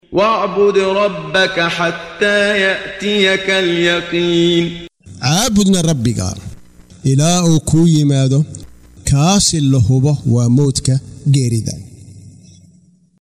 Waa Akhrin Codeed Af Soomaali ah ee Macaanida Suuradda Al-Xijr ( Buurta Dhagaxa ) oo u kala Qaybsan Aayado ahaan ayna la Socoto Akhrinta Qaariga Sheekh Muxammad Siddiiq Al-Manshaawi.